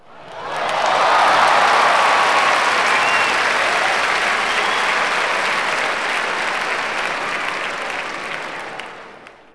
1 channel
clap_047.wav